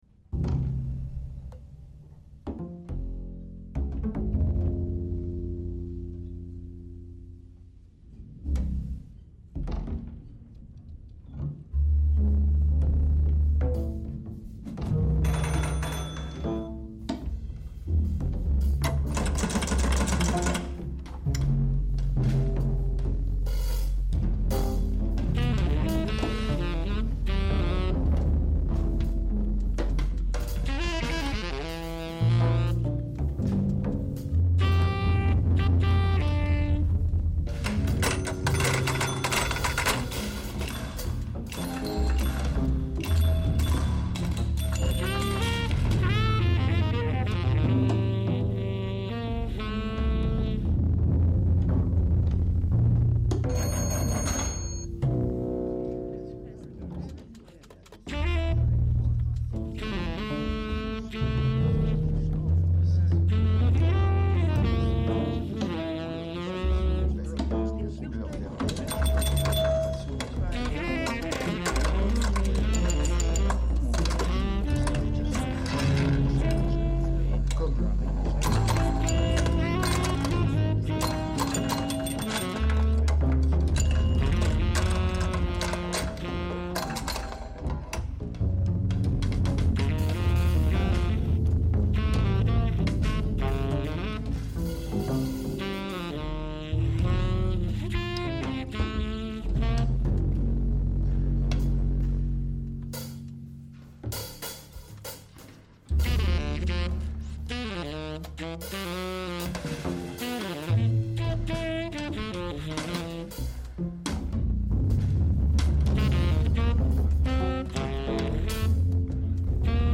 Additional (live mashed) sonic fictions